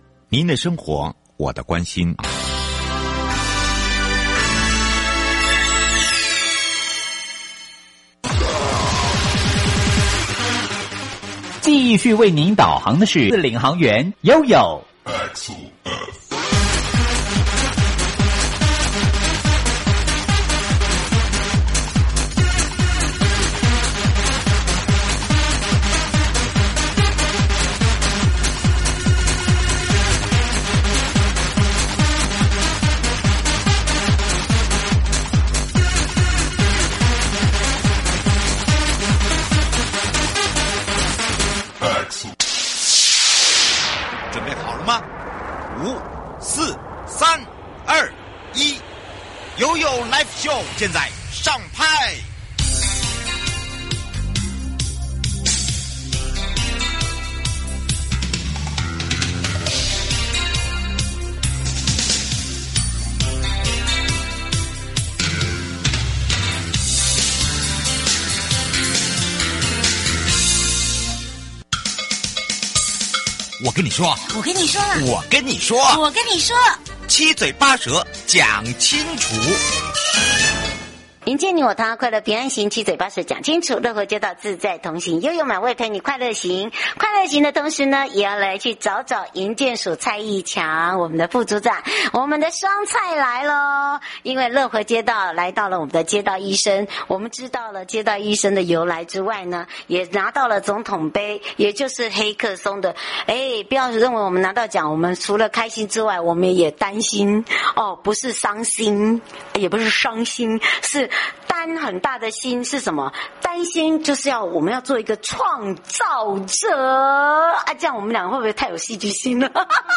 受訪者： 營建你我他 快樂平安行-主題：2022總統盃黑客松-用大數據提升民生安全(下集) 從、路障排除到淨零